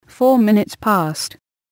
こちらは つづりを入力すると、その通りに話してくれるページです。
そこでAPR9600のAnalogInputにPCのLINEOUTを接続したところ、 適度な音量で再生ができるレベルになりました。